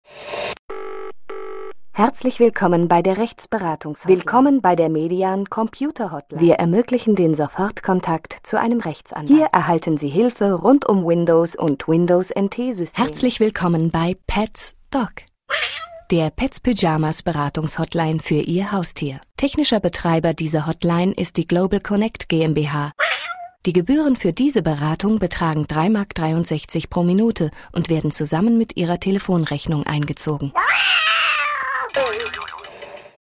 Sprecherin deutsch. Warme, vielseitige Stimme, Schwerpunkte: Lesungen, musikalische Lesungen, Rezitationen,
Sprechprobe: Industrie (Muttersprache):
Female voice over artist German